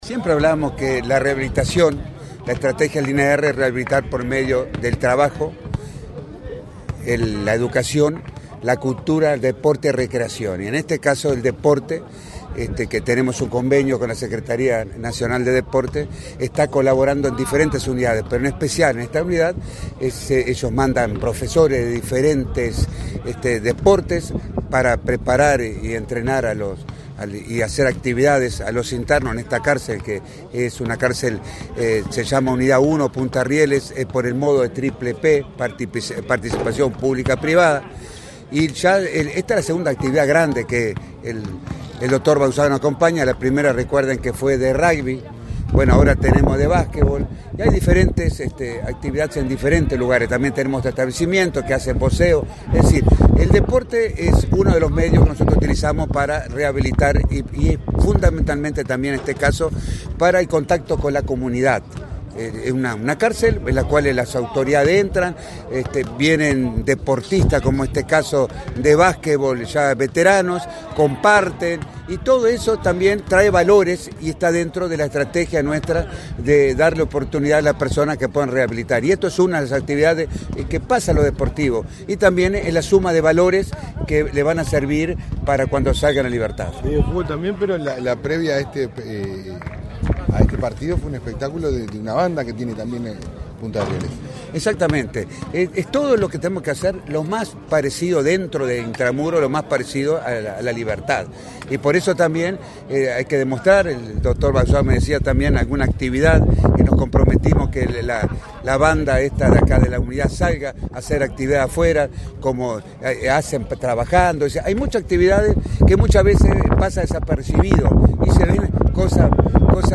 Declaraciones del director del INR
Declaraciones del director del INR 20/09/2022 Compartir Facebook X Copiar enlace WhatsApp LinkedIn Este martes 20, en la unidad n.° 1, de Punta de Rieles, se disputó un partido de básquetbol entre un equipo integrado por personas privadas de libertad y otro, de la Unión de Veteranos de Básquetbol de Uruguay. El director del Instituto Nacional de Rehabilitación (INR), Luis Mendoza, dialogó con la prensa acerca de la importancia de este tipo de eventos.